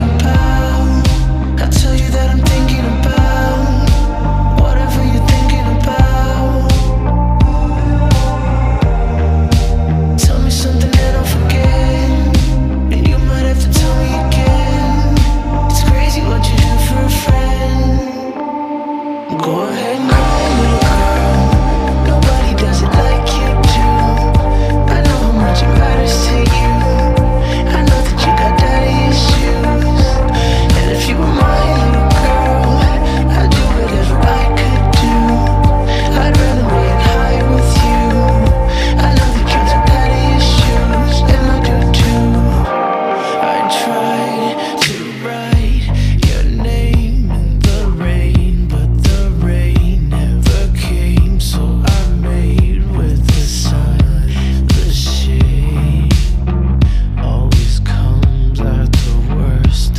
Романтические рингтоны, Зарубежные рингтоны